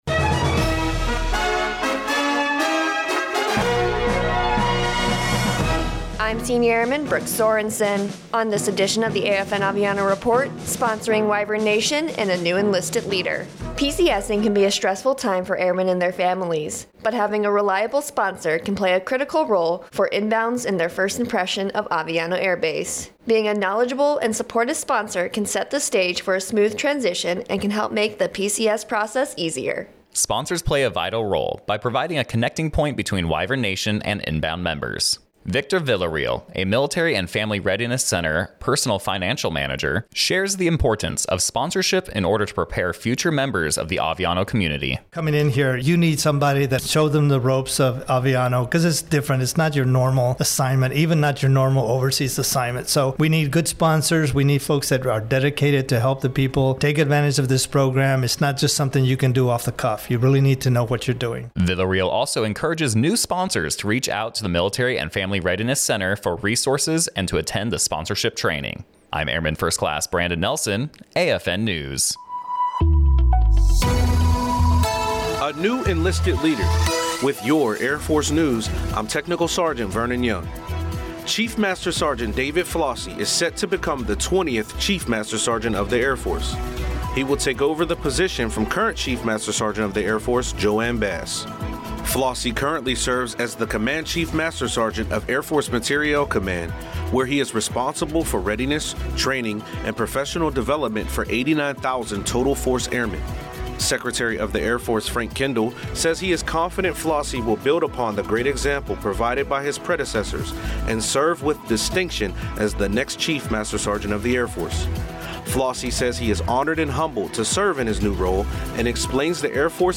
American Forces Network (AFN) Aviano radio news reports on the importance of sponsorship for inbound members at Aviano Air Base.